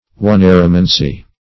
Search Result for " oneiromancy" : Wordnet 3.0 NOUN (1) 1. divination through the interpretation of dreams ; The Collaborative International Dictionary of English v.0.48: Oneiromancy \O*nei"ro*man`cy\, n. [Gr.